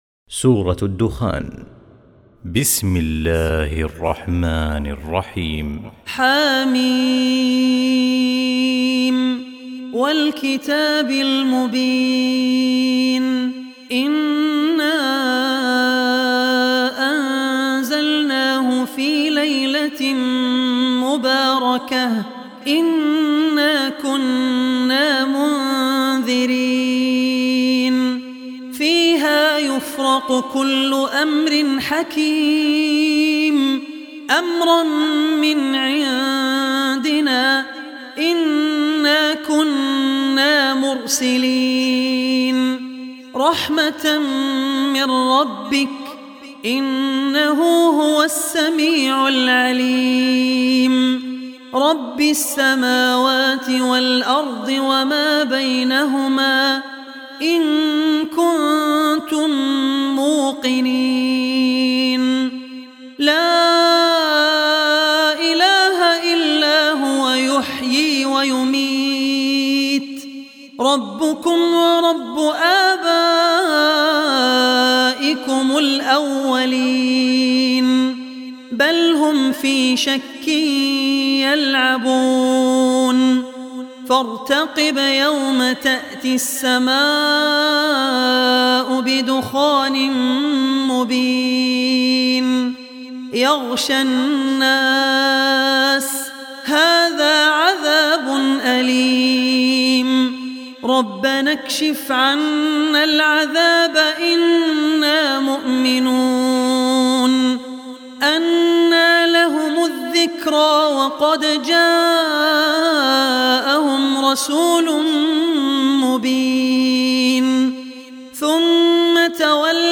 Surah Dukhan Recitation by Abdur Rehman Al Ossi
Surah Dukhan, listen online mp3 tilawat / recitation in Arabic recited by Sheikh Abdul Rehman Al Ossi.
44-surah-dukhan.mp3